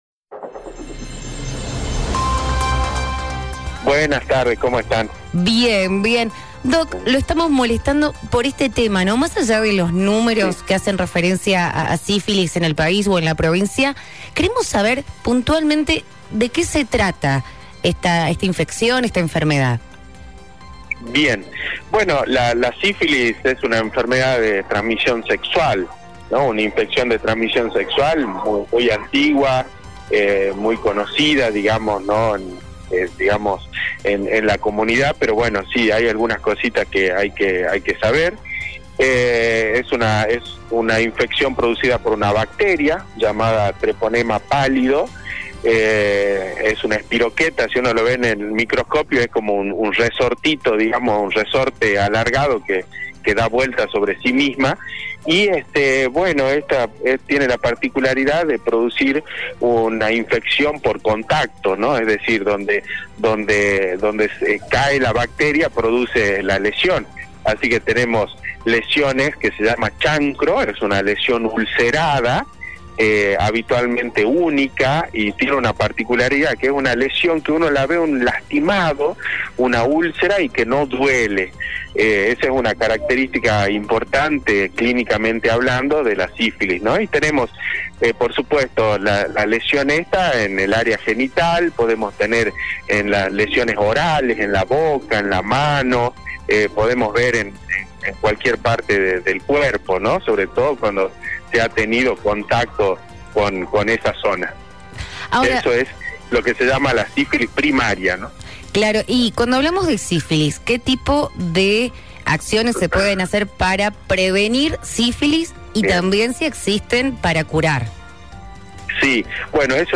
En el podcast de hoy hablamos con el doctor infectó logo